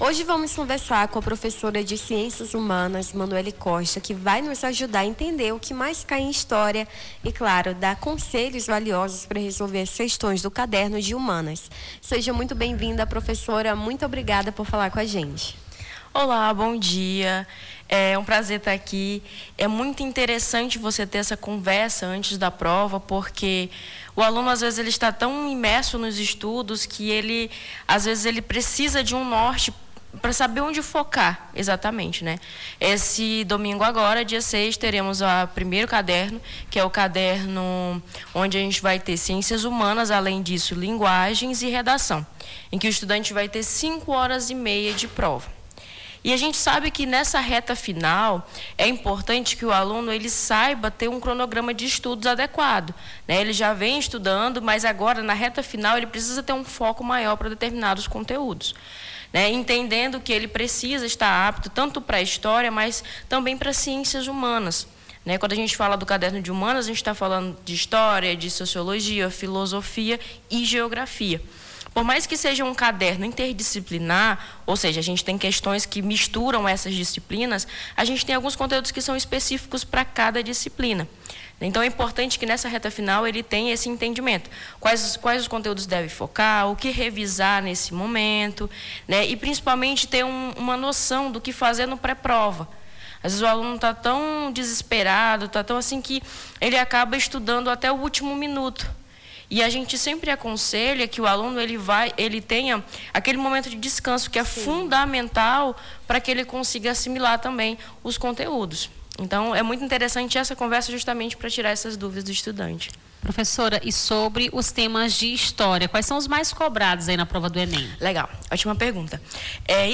O Jornal da Manhã conversou com a a professora de ciências humanas
ENTREVISTA